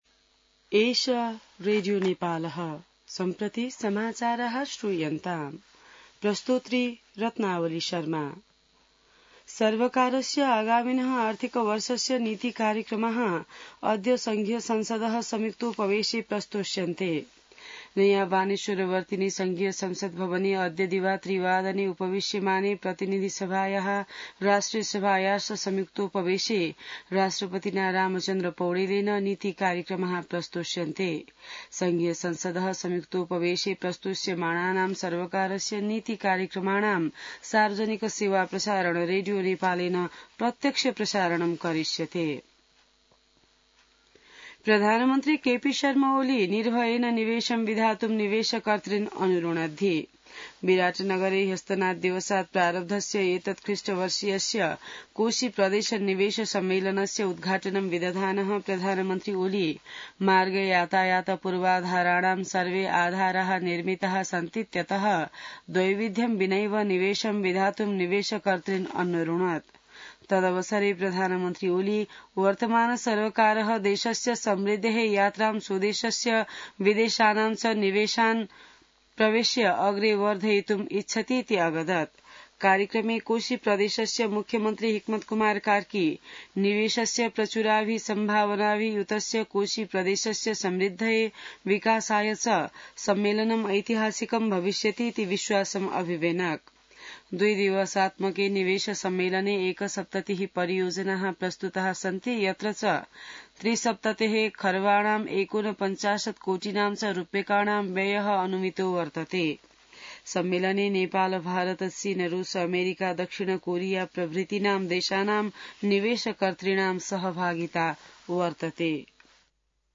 संस्कृत समाचार : १९ वैशाख , २०८२